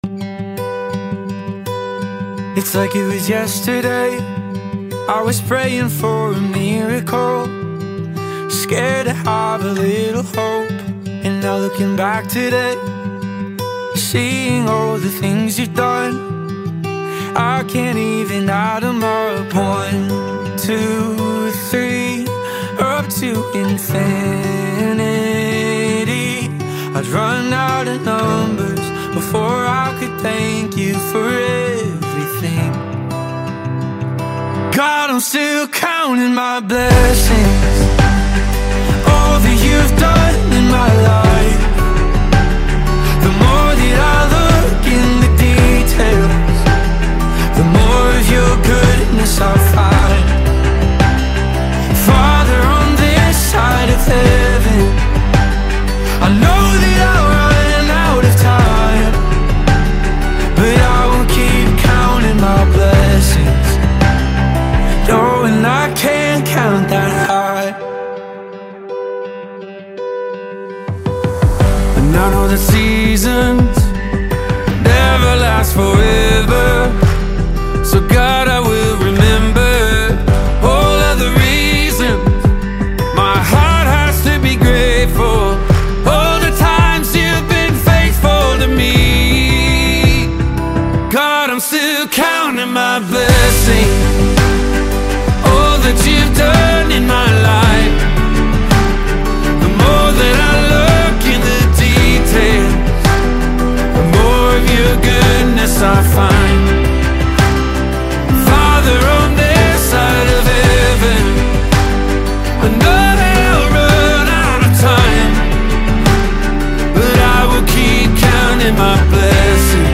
Foreign and International Gospel Music
Christian worship song